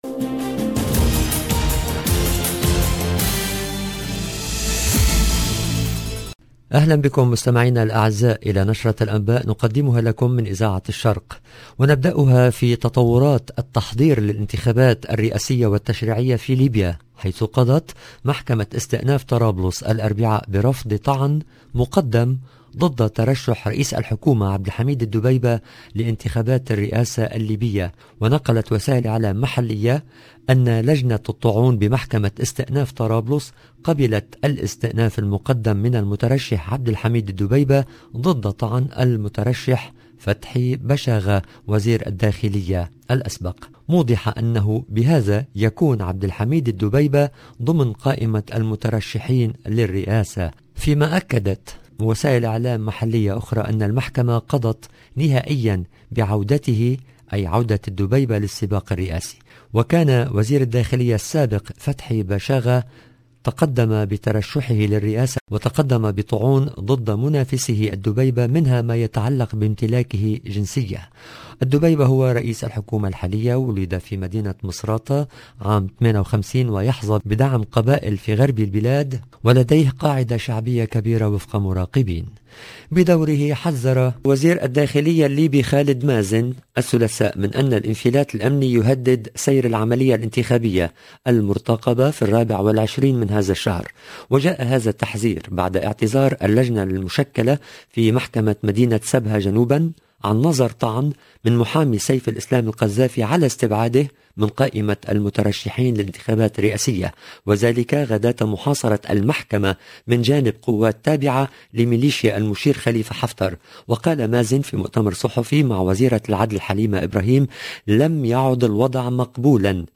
LE JOURNAL EN LANGUE ARABE DU SOIR DU 1/12/21